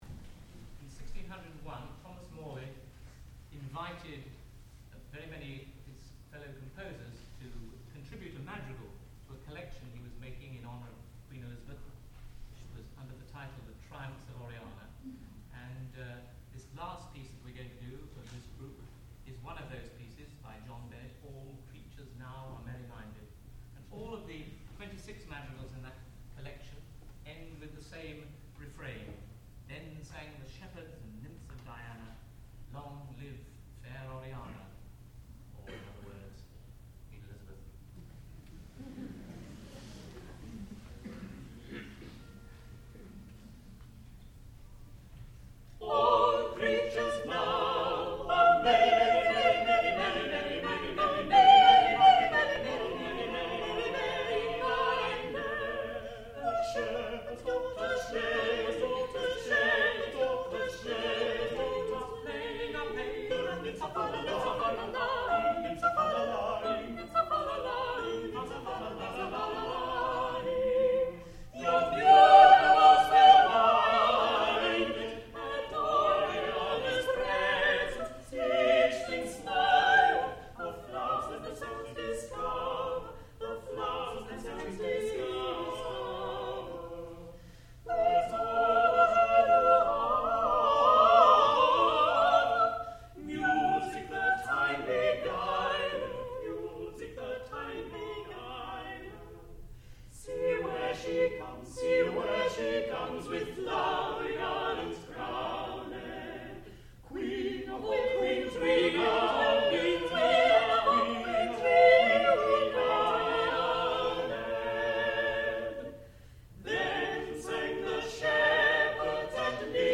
sound recording-musical
classical music
tenor
soprano
lute